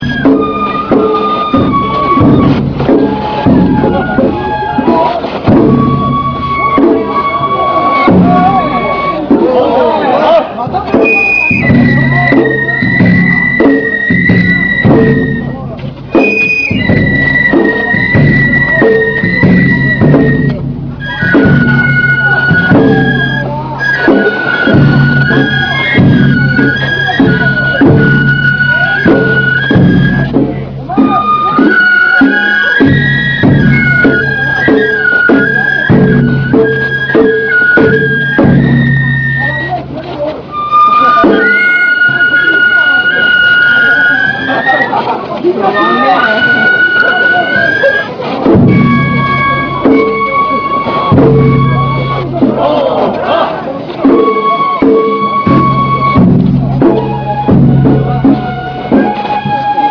帰路は、ゆっくり"帰り囃子"で、さぁ〜行こう！
まだ半道中ですから未だ、先はありまが、山車囃子の曲は、能管でなく、篠笛で演奏する